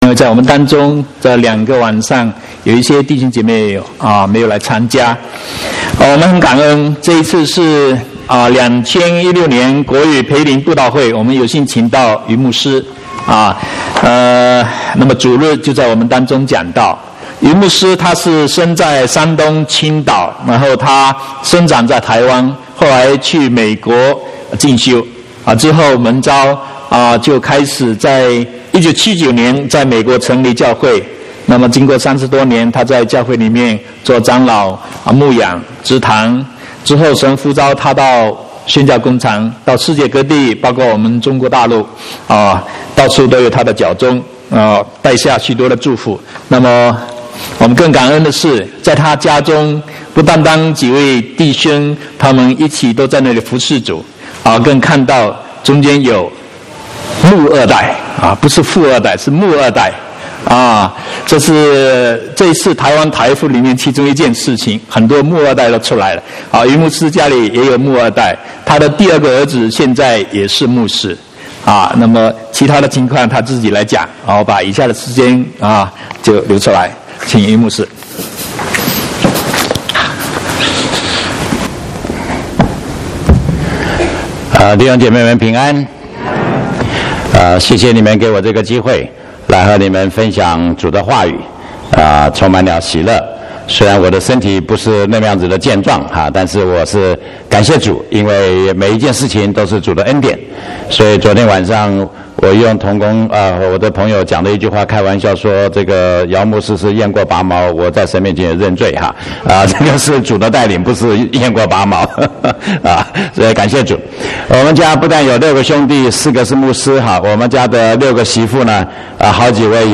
18/9/2016國語堂講道